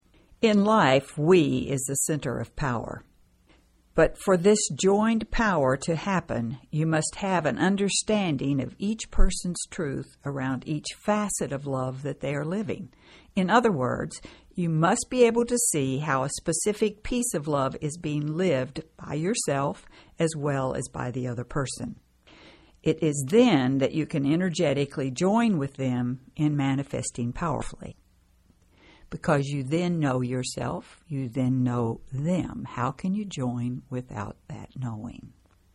This recording is a unique format of a conversational presentation and intimate discussion, complete with real-life examples that gives you an unparalleled grasp of this ground-breaking material!